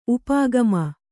♪ upāgama